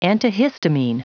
Prononciation du mot antihistamine en anglais (fichier audio)
Prononciation du mot : antihistamine